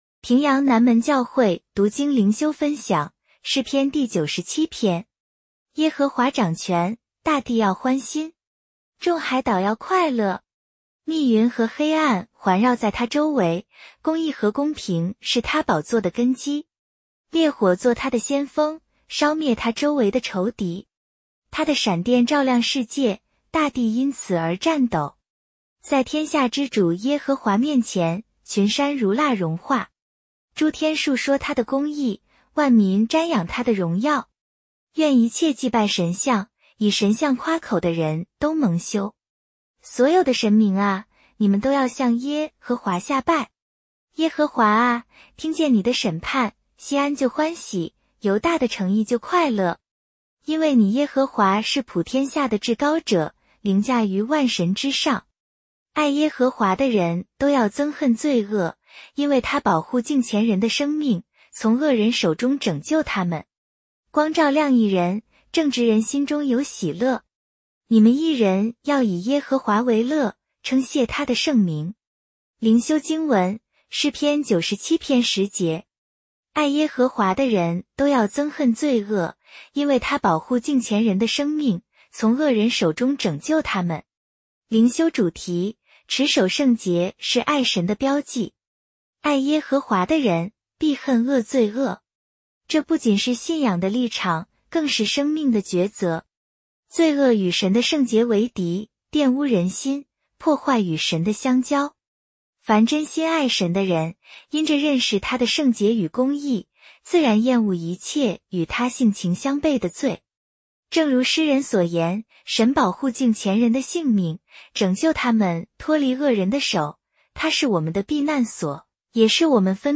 普通话朗读——诗97